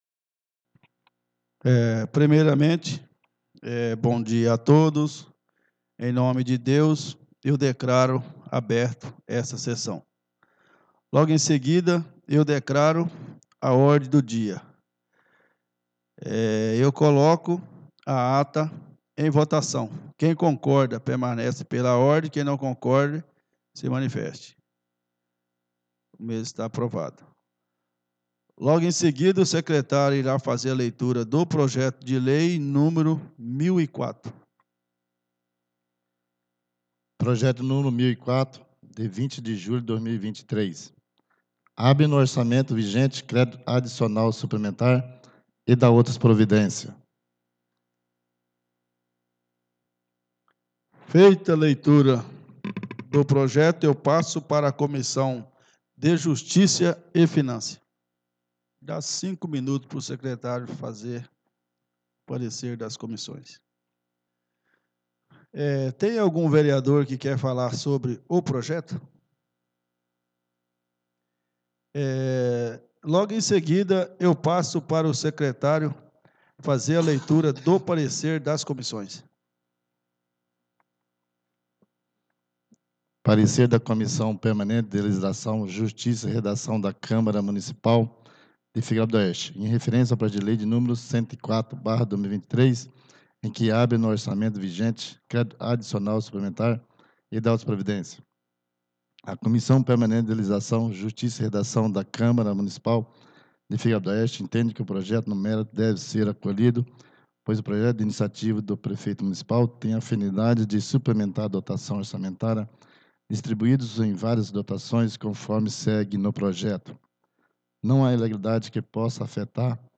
SESSÃO EXTRAORDINÁRIA DE 26 DE JULHO DE 2023